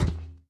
Minecraft Version Minecraft Version snapshot Latest Release | Latest Snapshot snapshot / assets / minecraft / sounds / mob / irongolem / walk4.ogg Compare With Compare With Latest Release | Latest Snapshot
walk4.ogg